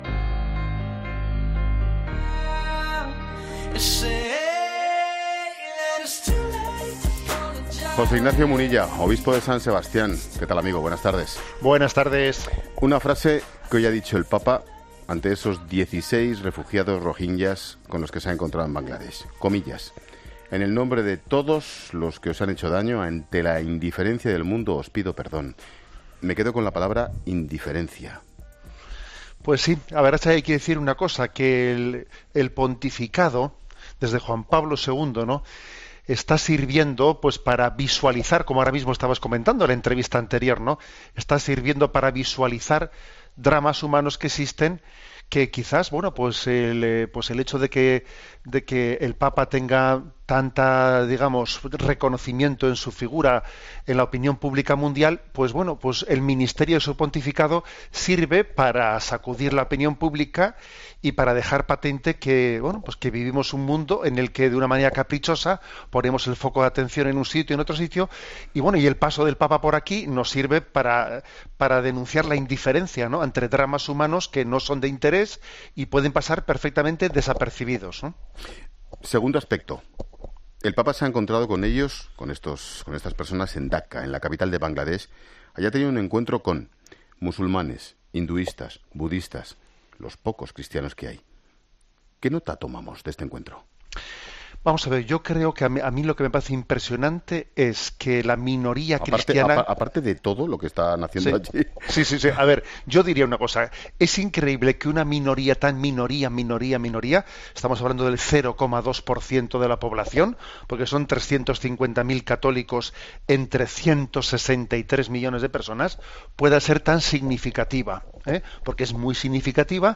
El obispo de San Sebastián, Monseñor José Ignacio Munilla, ha explicado en 'La Tarde' que el viaje que ha realizado el Papa Francisco a Birmania y Bangladesh, donde los cristianos son una minoría, demuestran que "la iglesia está siendo un lugar de encuentro", y se está mostrando como "casa de paz y casa de comunión".